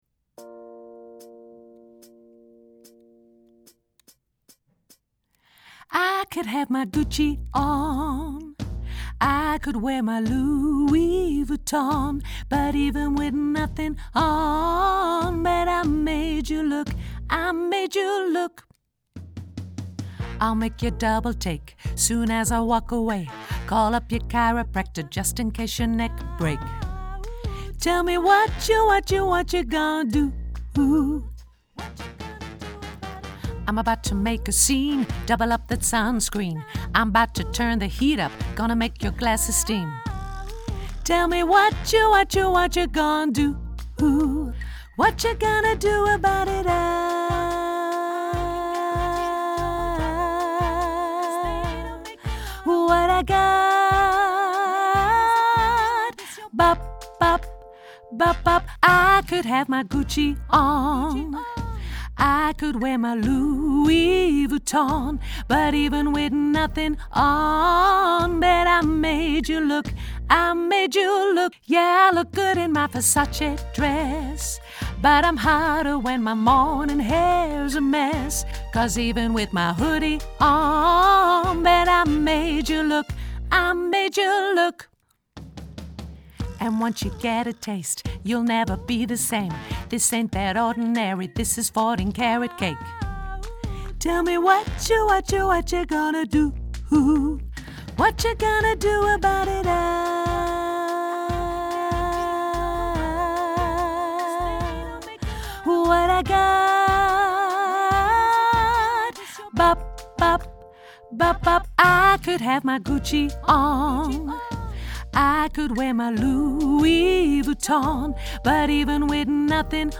alt